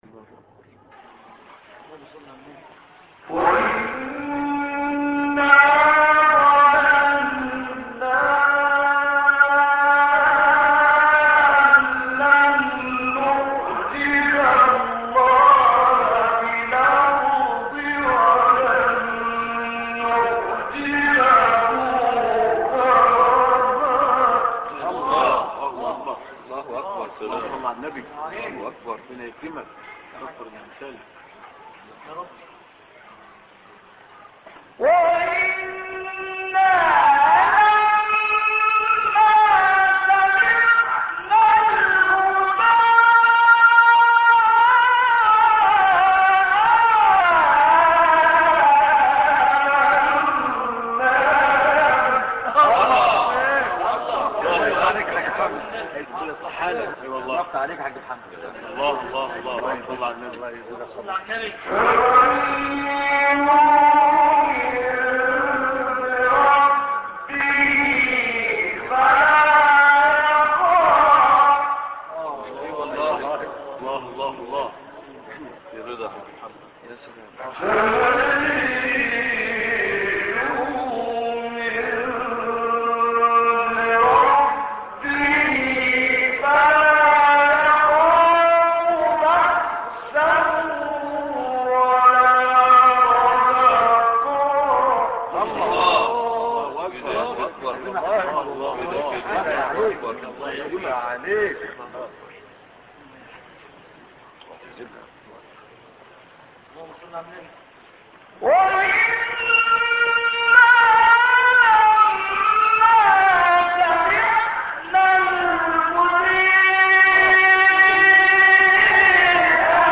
نغمة سيكا